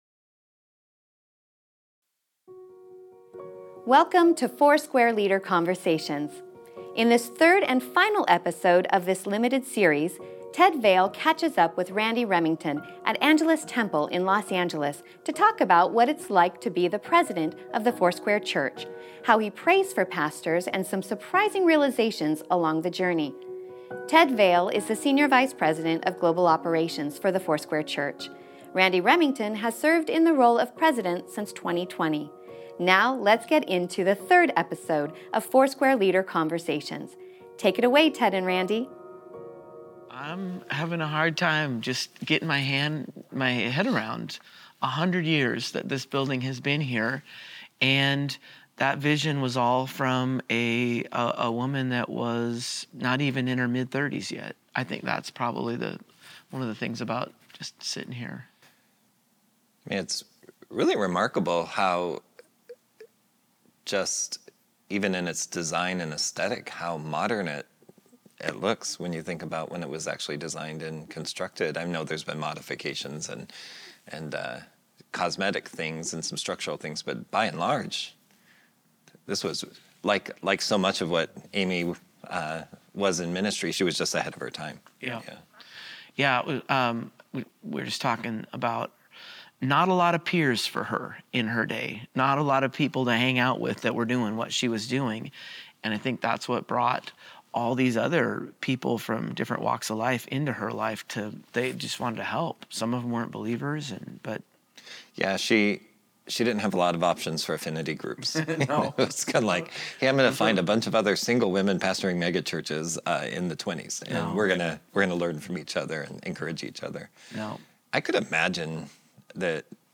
Episode 3: Conversations about The Foursquare Church and its history + relevance today